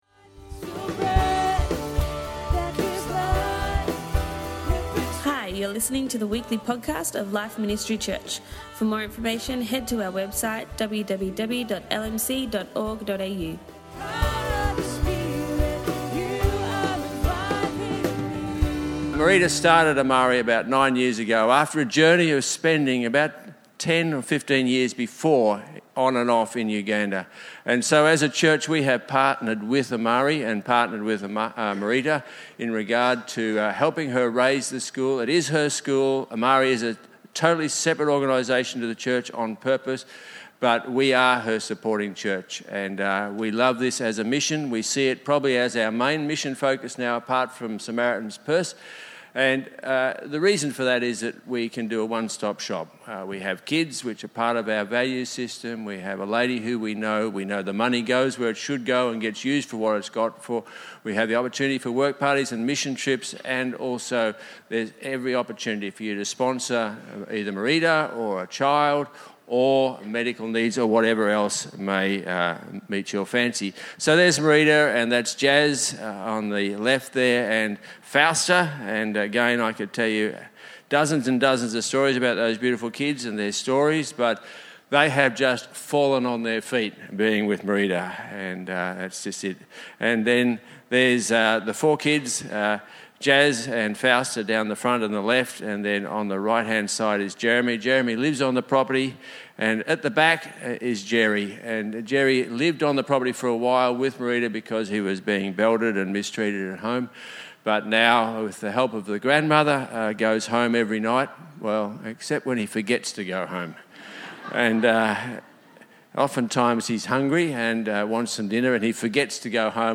Each year we hear a report from the Amari Work Party.